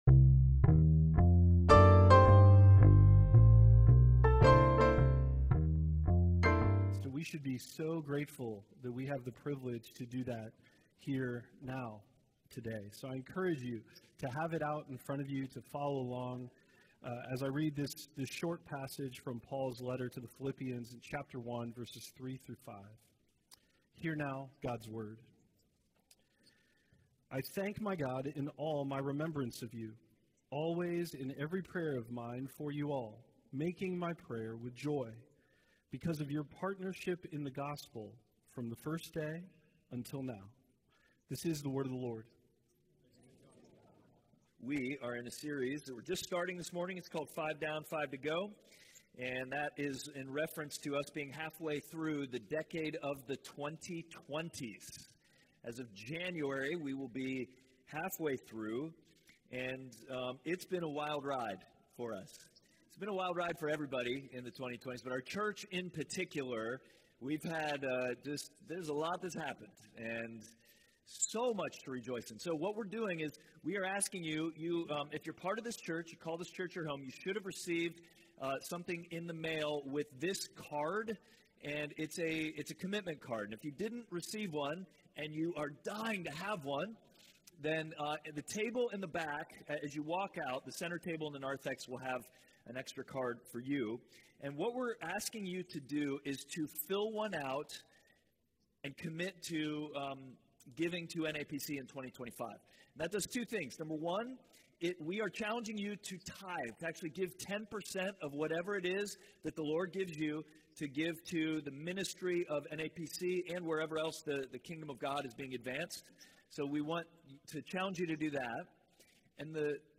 Five to Go Passage: Philippians 1:3-5 Service Type: Sunday Worship « The Third Person